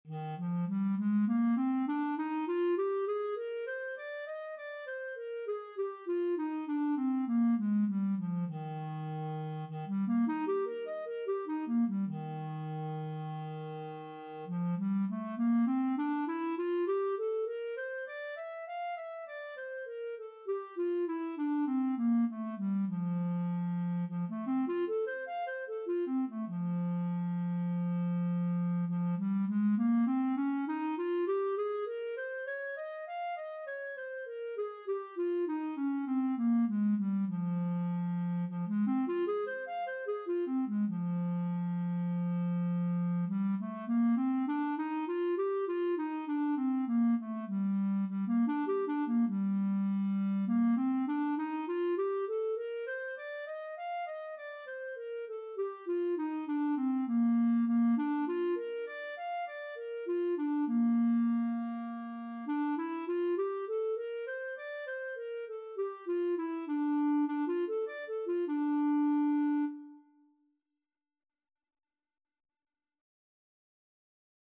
Clarinet scales and arpeggios - Grade 2
Eb major (Sounding Pitch) F major (Clarinet in Bb) (View more Eb major Music for Clarinet )
Eb4-F6
4/4 (View more 4/4 Music)
clarinet_scales_grade2_CL.mp3